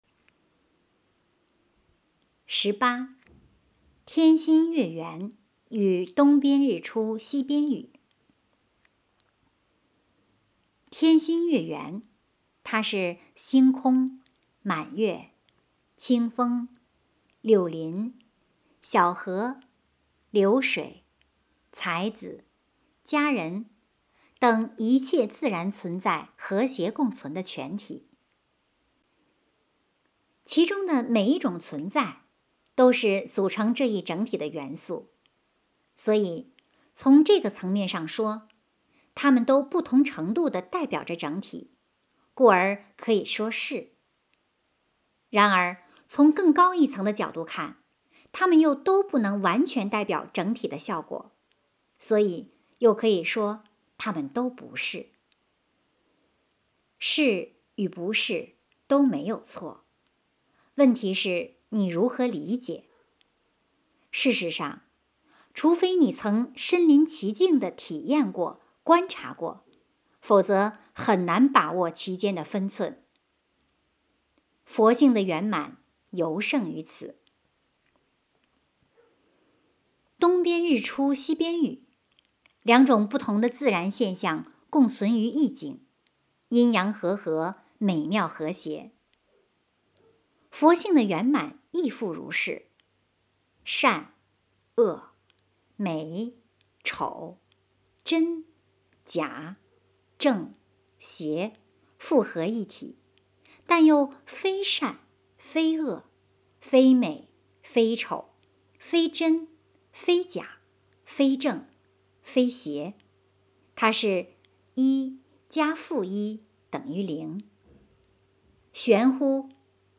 有声读物 - 实修世界